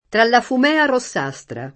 fumea
fumea [ fum $ a ] s. f.